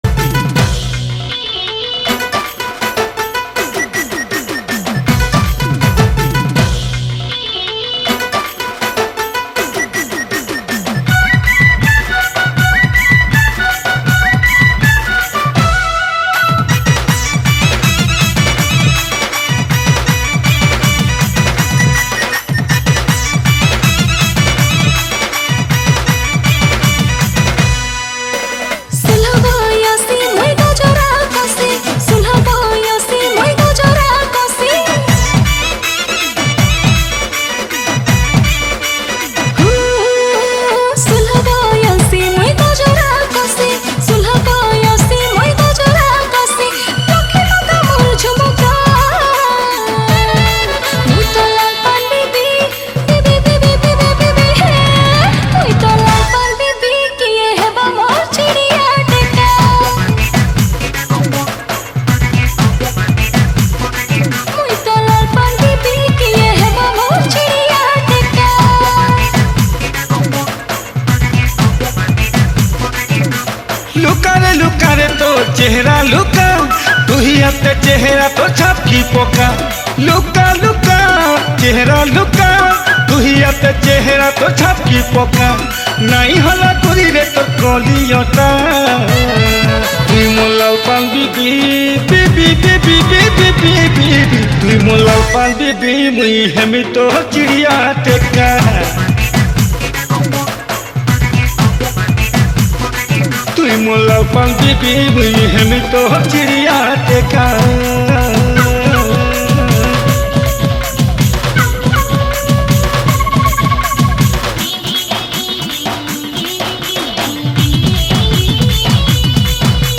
Category: New Sambalpuri Folk Song 2021